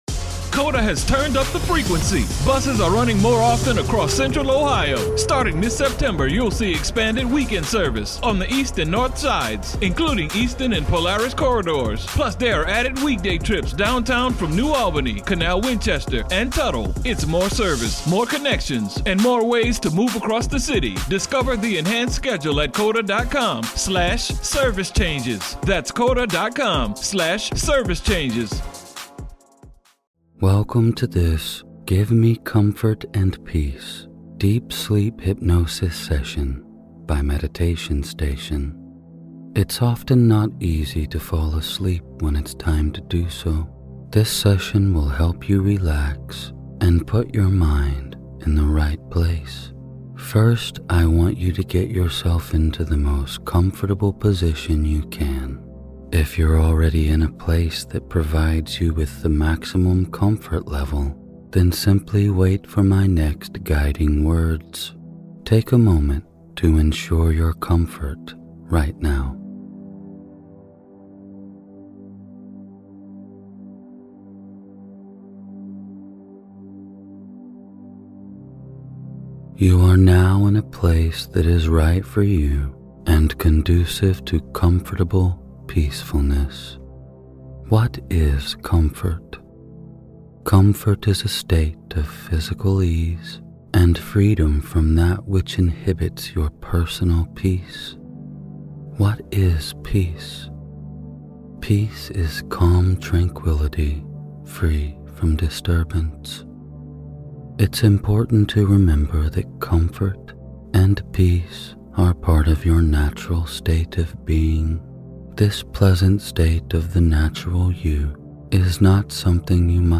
Find your inner comfort and restore peace while getting the best sleep possible with Meditation Station's guided sleep hypnosis.